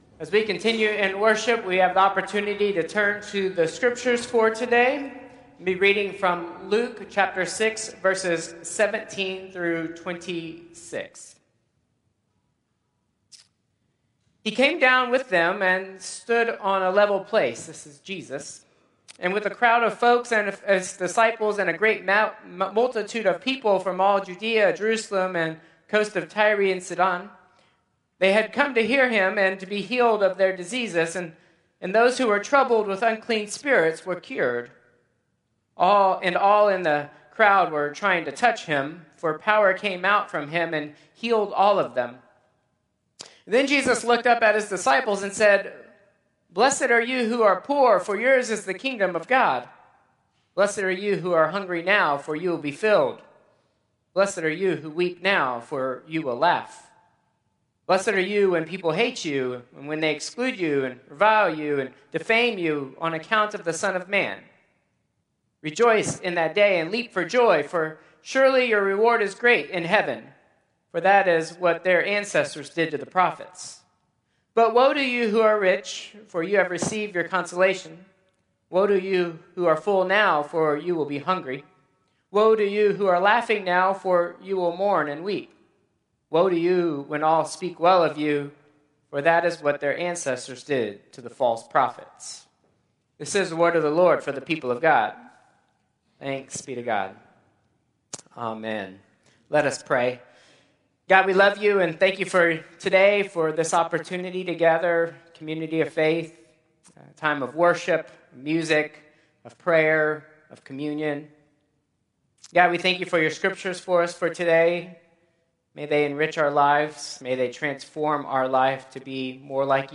Traditional Service 2/16/2025